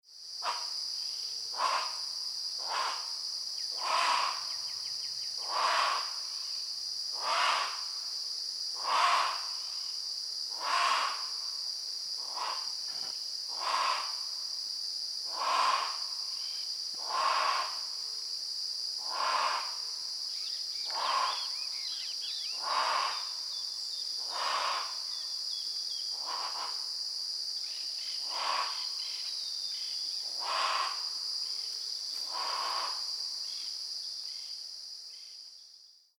Jacuguaçu (Penelope obscura)
Nome em Inglês: Dusky-legged Guan
Fase da vida: Adulto
Localidade ou área protegida: Delta del Paraná
Condição: Selvagem
Certeza: Observado, Gravado Vocal